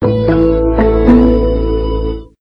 MinderiaOS Pre-Alpha Startup.wav